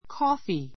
kɔ́ːfi コ ーふィ ｜ kɔ́fi コ ふィ